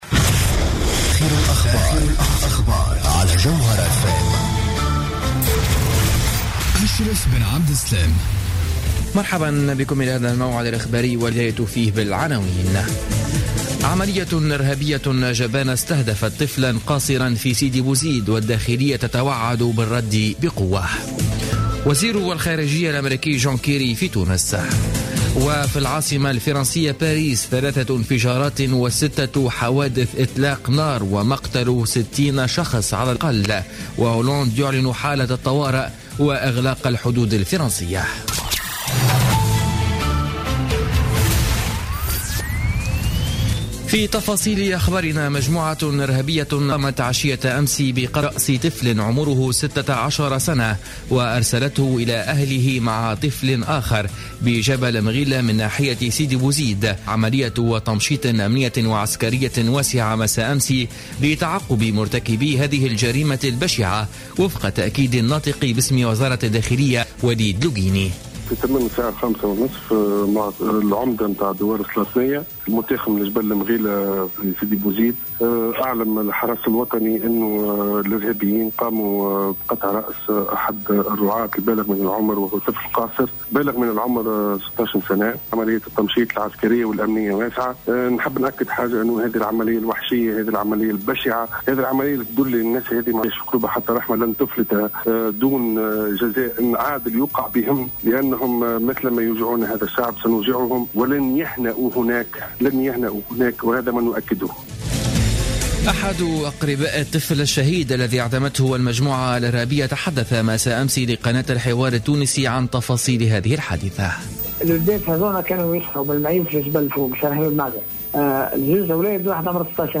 نشرة أخبار منتصف الليل ليوم السبت 14 نوفمبر 2015